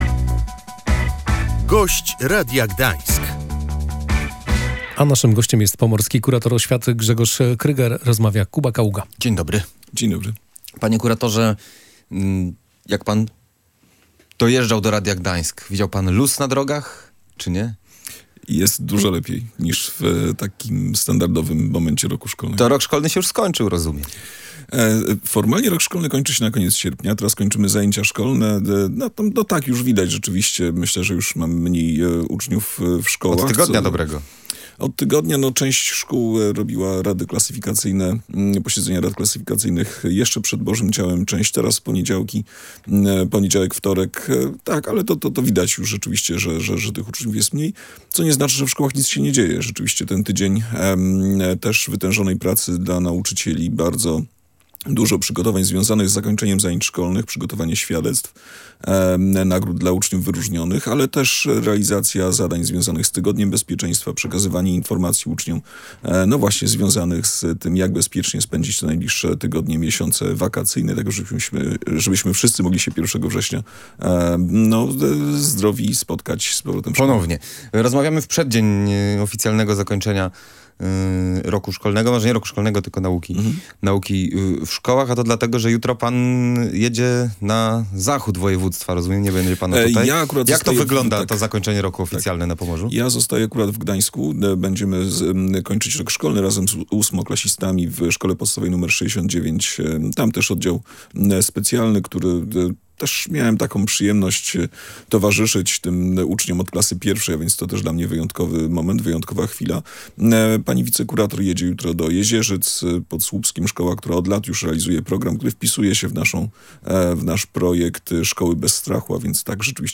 Miniony rok szkolny był czasem przygotowywania się do dużych zmian, czyli do reformy programowej – mówił w Radiu Gdańsk pomorski kurator oświaty Grzegorz Kryger.